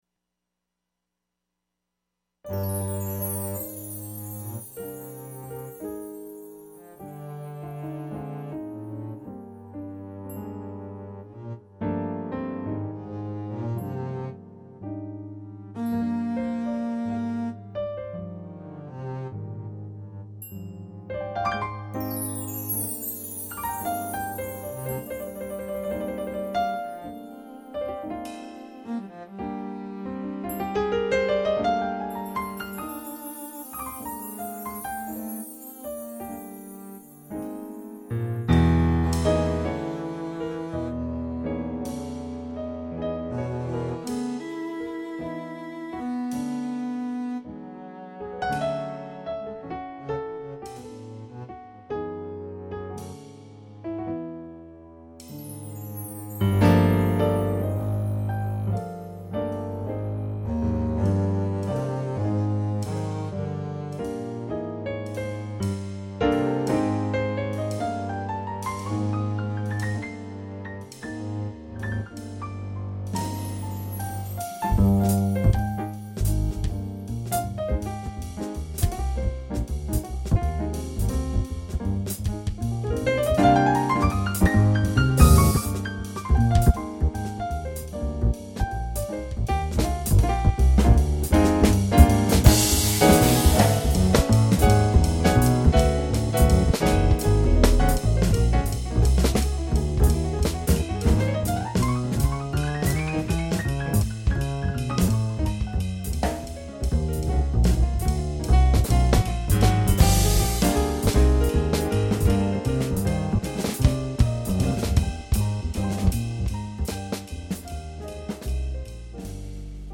Jazz (trio)